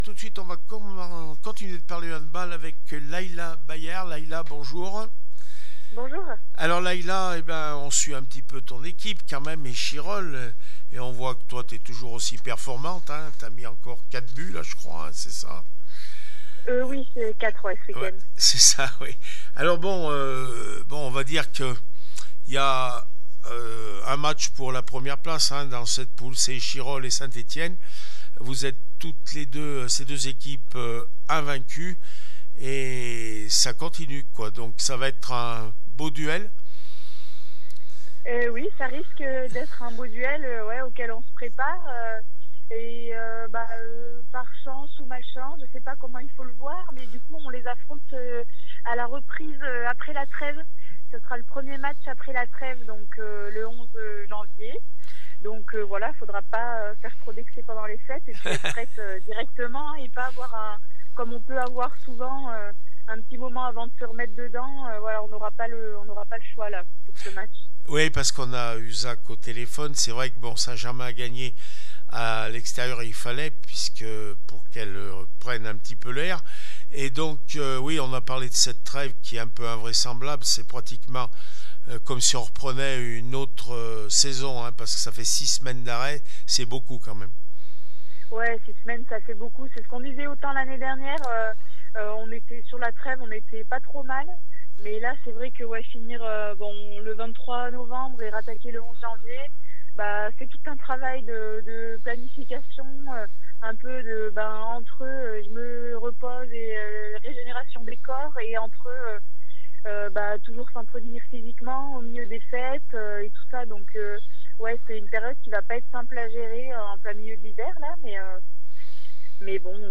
24 novembre 2024   1 - Sport, 1 - Vos interviews